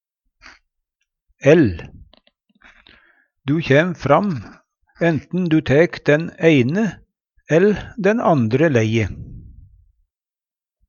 ell - Numedalsmål (en-US)